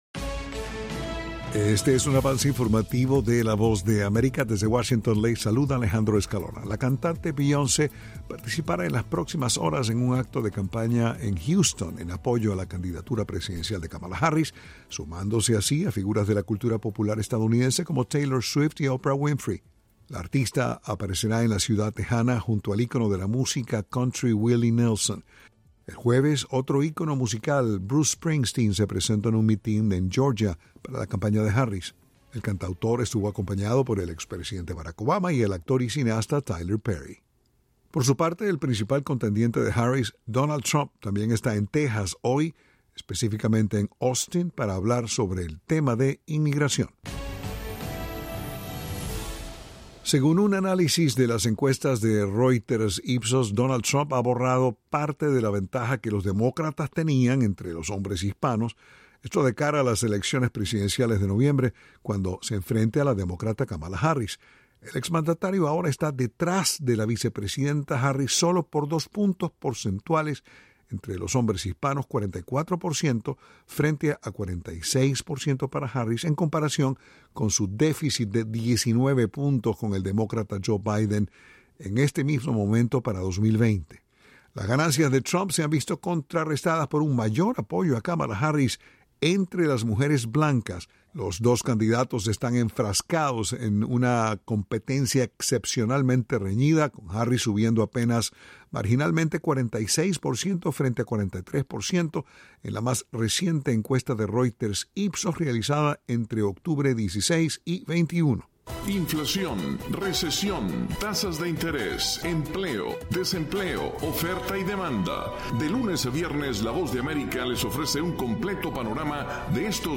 Avance Informativo
Este es un avance informativo presentado por la Voz de América en Washington.